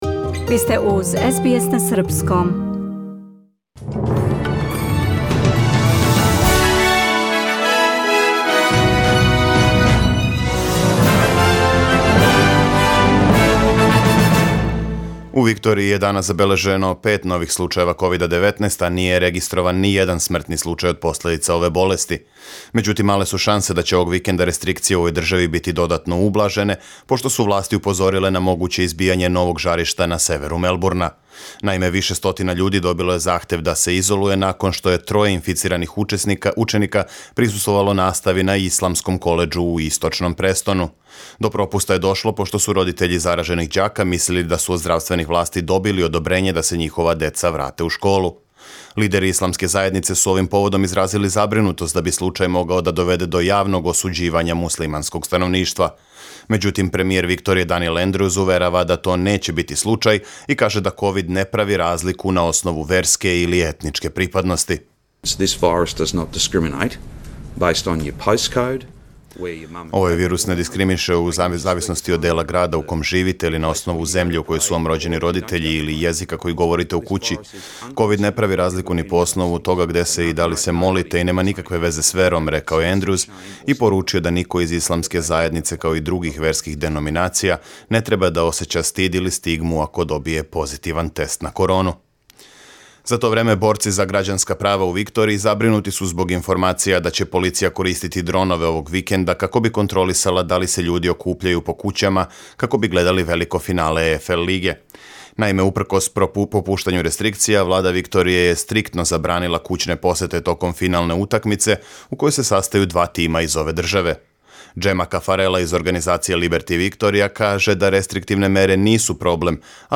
Преглед вести за 22. октобар 2020. године